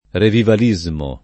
vai all'elenco alfabetico delle voci ingrandisci il carattere 100% rimpicciolisci il carattere stampa invia tramite posta elettronica codividi su Facebook revivalismo [ revival &@ mo ; all’ingl. revaival &@ mo ] s. m.